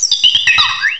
cry_not_lilligant.aif